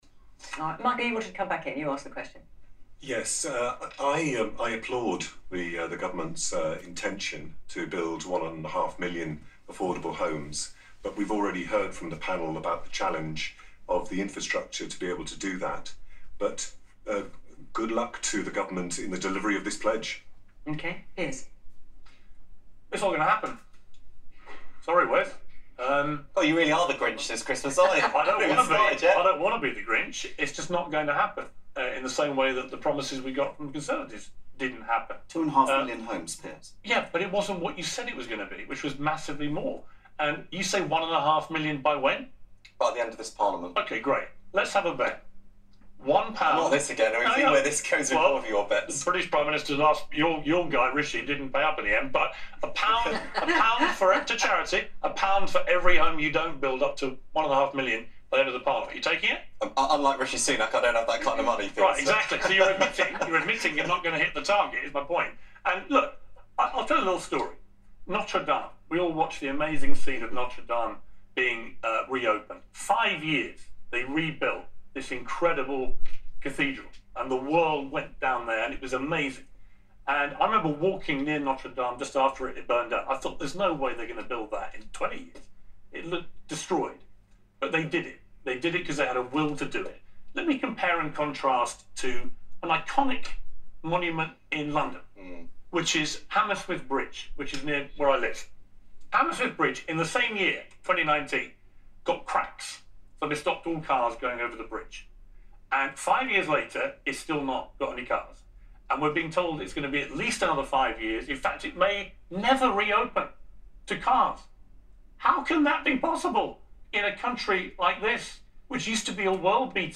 Piers Morgan QT: Piers Morgan on Question Time, summing up some of the problems we have in this country with social-management ineptitude and corruption. That is inability to get things done and the blatant exploitation of public funds by greedy, behind-the-scenes people.